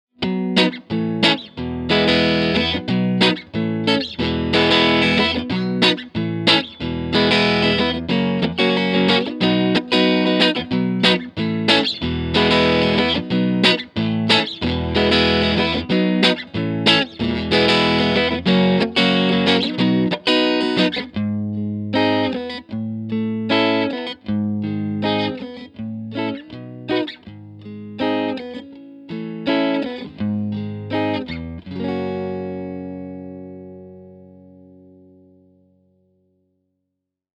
Mic was a single SM57, to Vintech (Neve-style) pre, to Apogee Rosetta 200 A/D, to the computer.
Ch.1 Clean was as follows - no MV, cut 3:00, vol 7:30, contour pos 2 (from left), munch/hi on the back, Lo input on the front.
TC15_Ch1_Clean_Dano_Neck.mp3